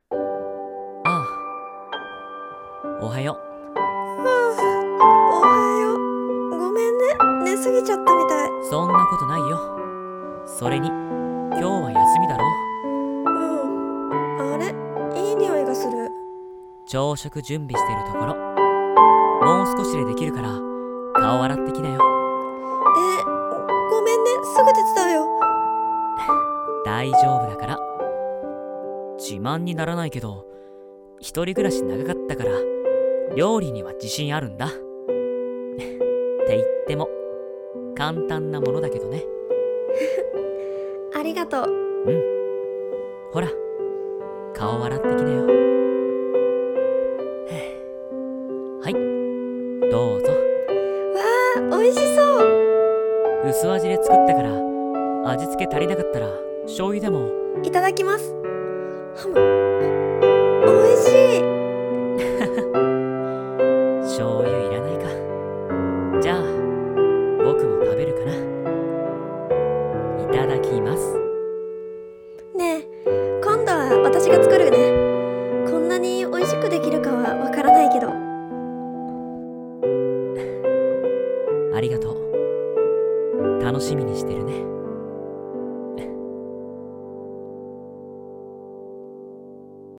【おはよう】※恋愛コラボ声劇